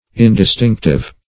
Search Result for " indistinctive" : The Collaborative International Dictionary of English v.0.48: Indistinctive \In`dis*tinc"tive\ ([i^]n`d[i^]s*t[i^][ng]k"t[i^]v), a. Having nothing distinctive; common.